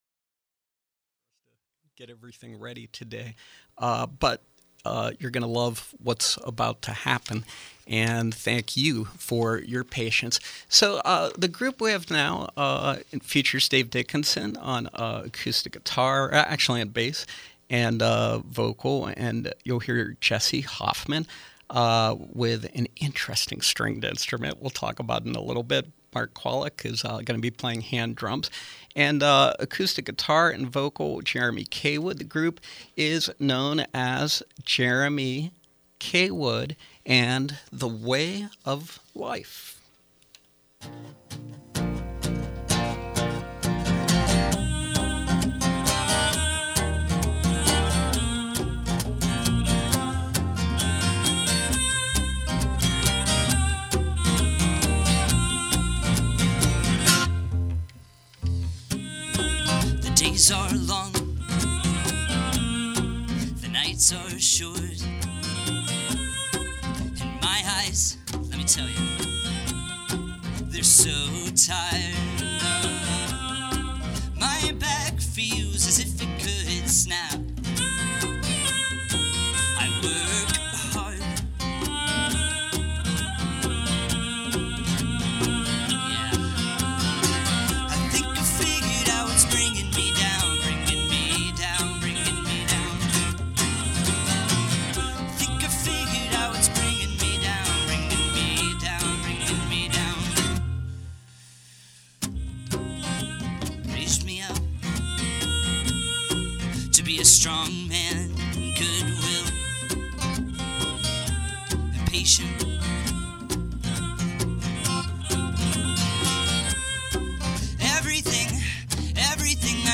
Pittsburgh-based singer/songwriter
bass
violin
percussion